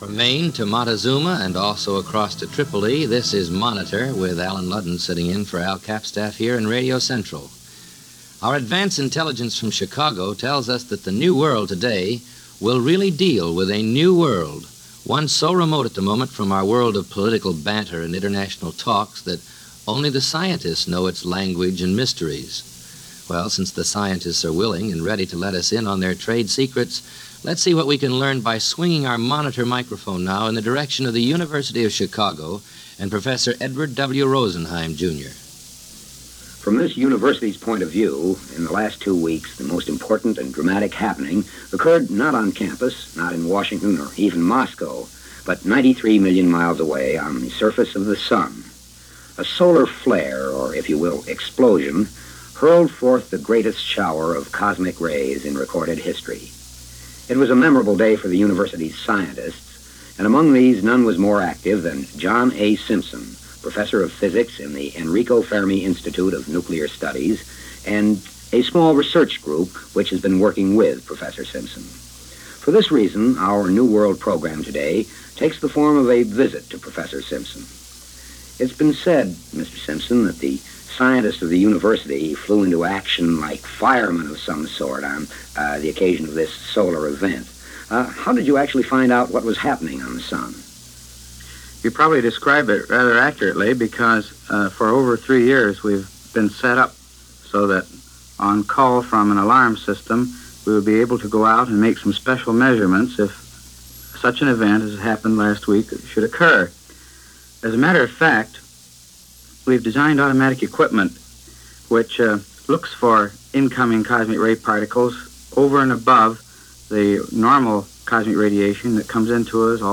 Shortly after that historic Solar Flare episode, the NBC Radio series Monitor ran a special program as part of their New World segment, where scientists from the Enrico Fermi Institute are interviewed regarding the unusual Solar activity late in February of that year.
NBC-Radio-Monitor-New-World-March-4-1956.mp3